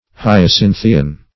Hyacinthian \Hy`a*cin"thi*an\